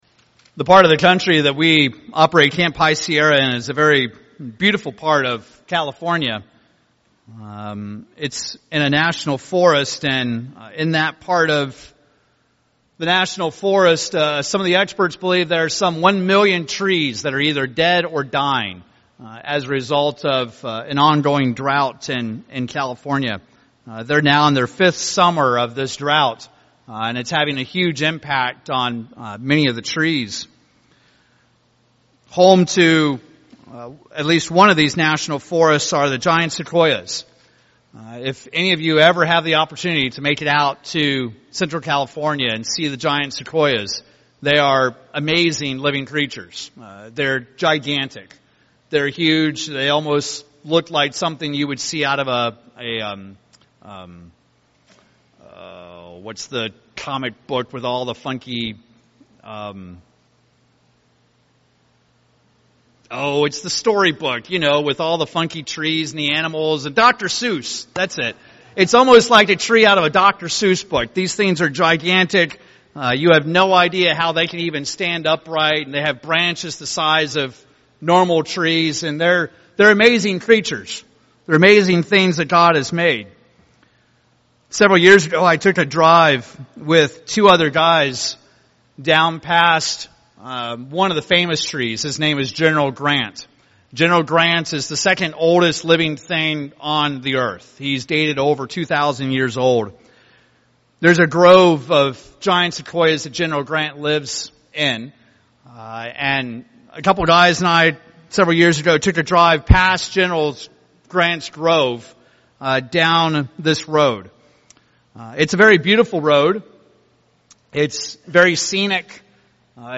In this sermon we discuss the importance of being on the right road.